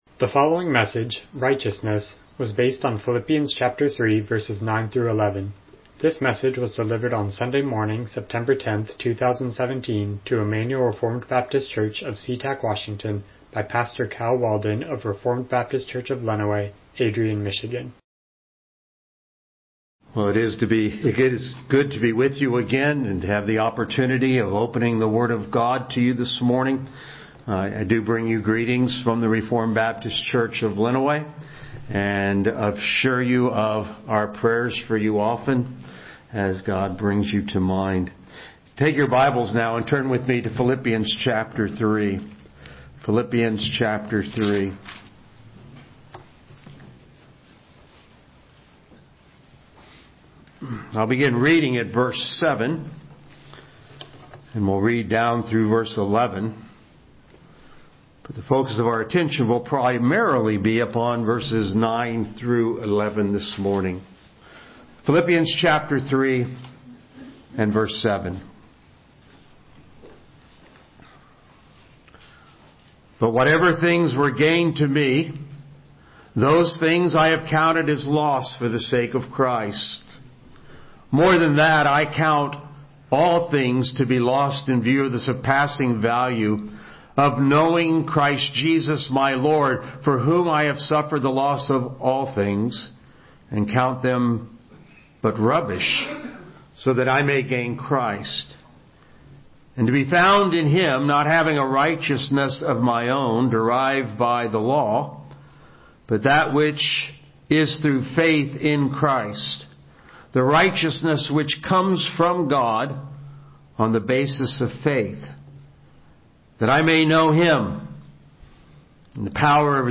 Miscellaneous Passage: Philippians 3:9-11 Service Type: Morning Worship « Church Membership